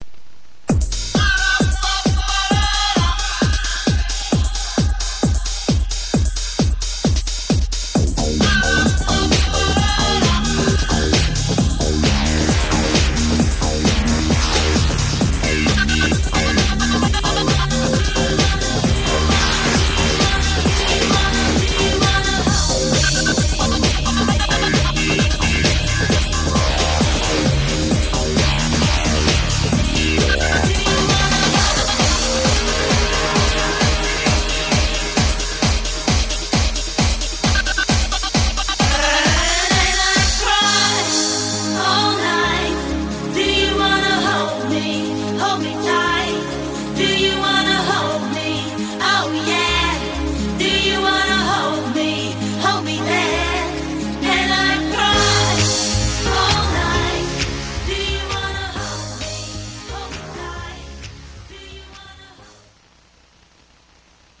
Mix (Edit)